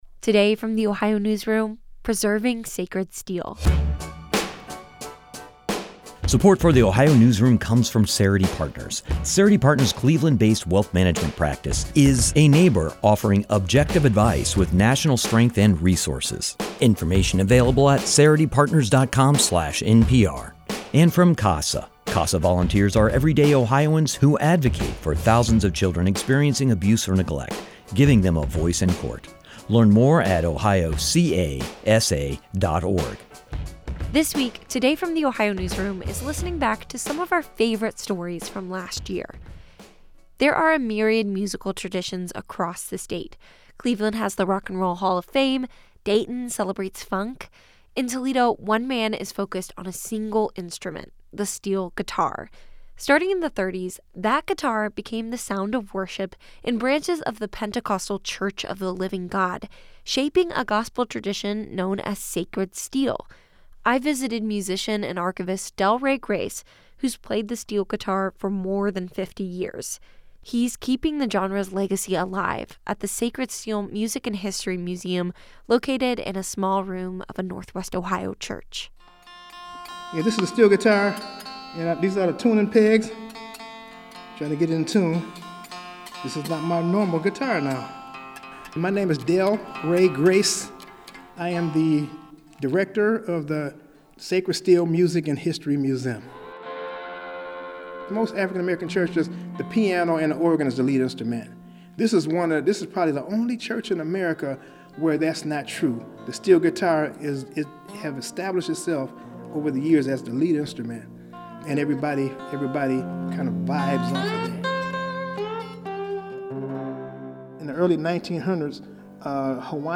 Its sliding tones were a key feature in odes to the Pacific island and became commonly known as the "Hawaiian guitar."
In one hand, he held a steel bar across the frets, allowing the guitar to slide effortlessly between notes, giving it its signature wail.
sacred-steel-archive-web.mp3